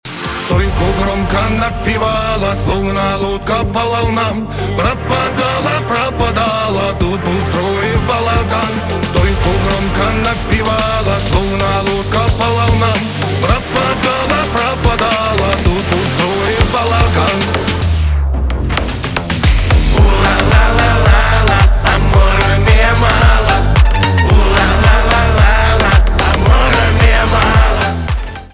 поп
битовые , басы
качающие